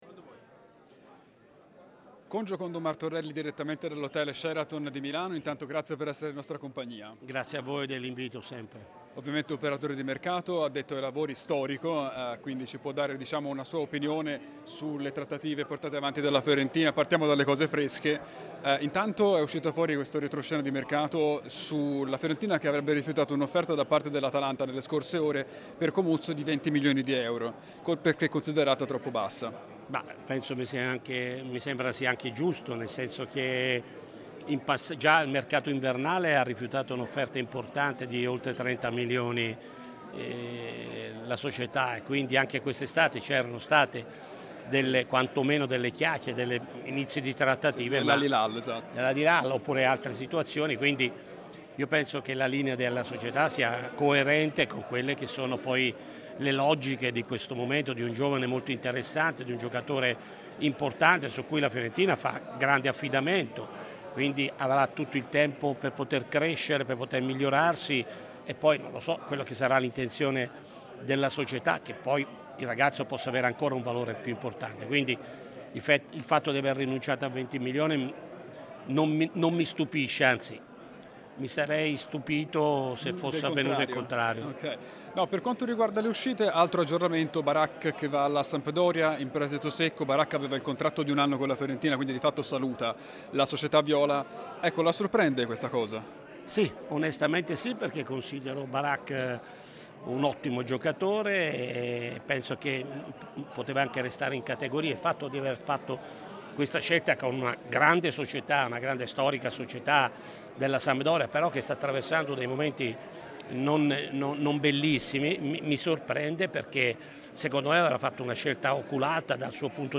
fonte dai nostri inviati a Milano
ha preso la parola su Radio FirenzeViola direttamente dall'Hotel Sheraton di Milano per commentare le mosse di questa campagna acquisti-cessioni della Fiorentina.